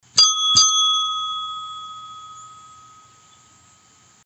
Звуки настольного звонка
Звон колокольчика на столе